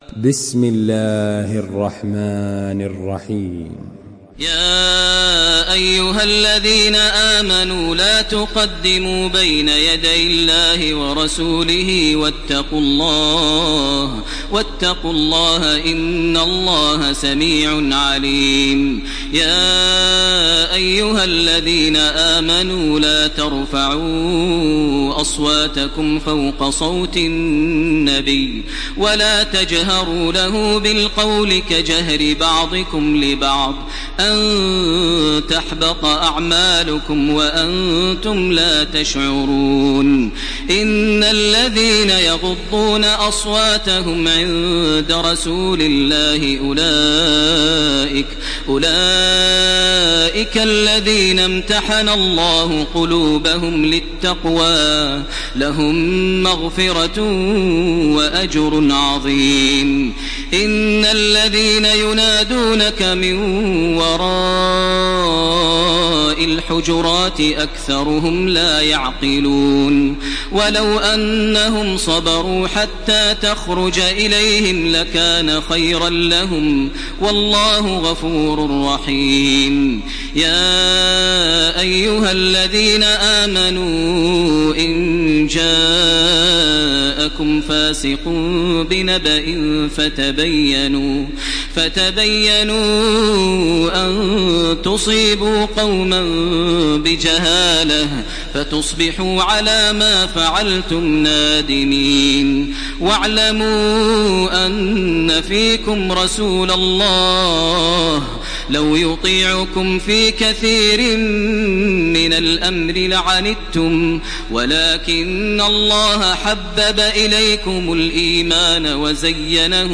تحميل سورة الحجرات بصوت تراويح الحرم المكي 1429
مرتل